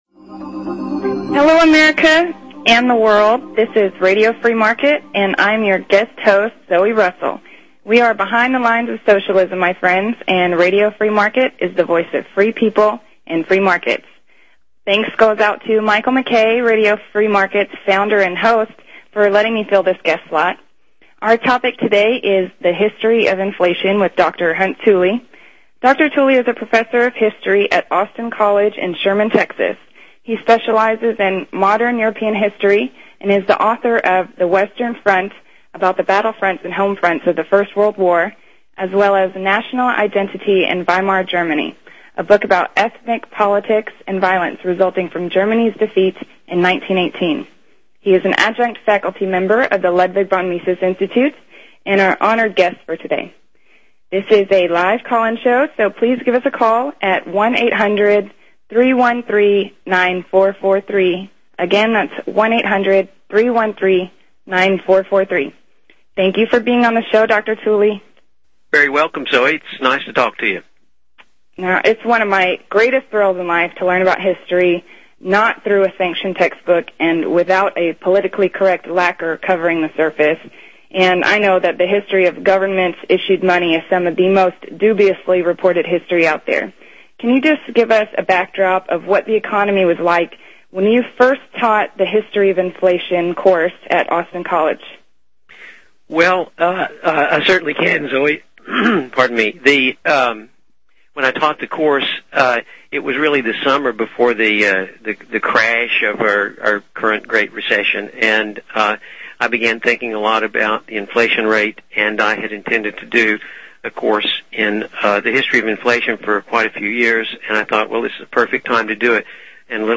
**Live Interview**